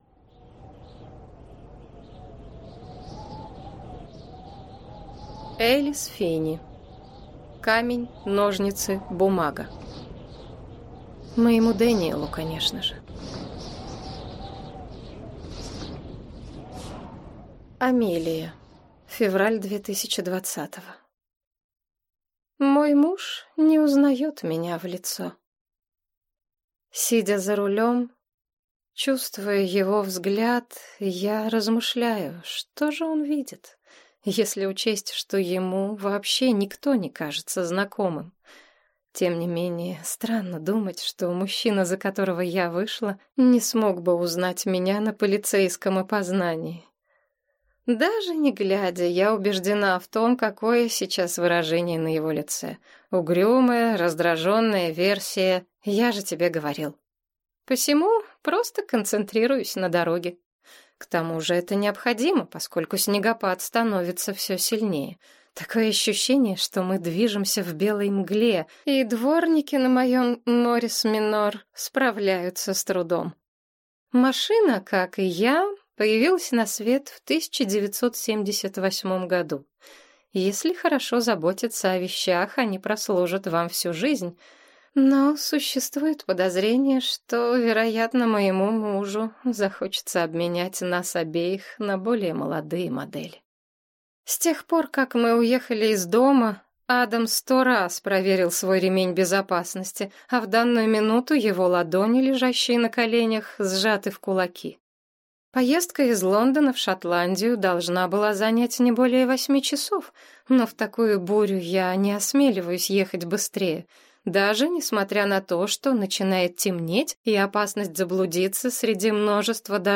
Аудиокнига Камень, ножницы, бумага | Библиотека аудиокниг